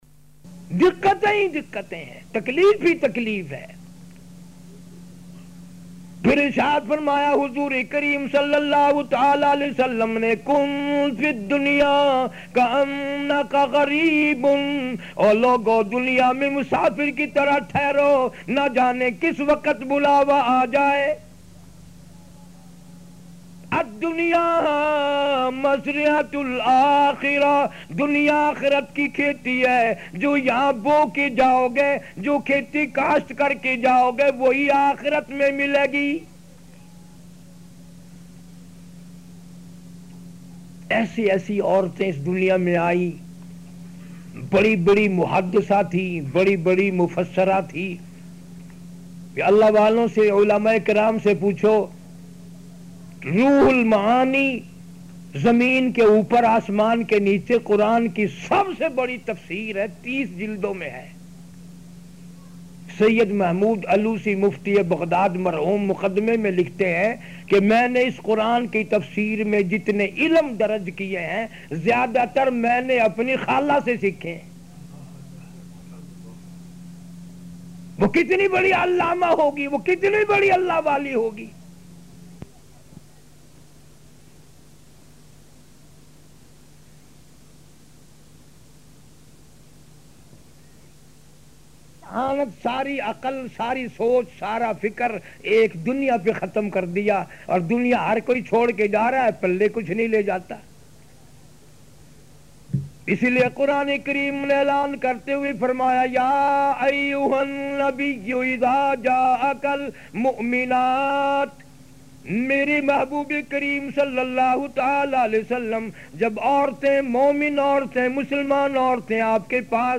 A DYNAMIC URDU LECTURE DELIVERED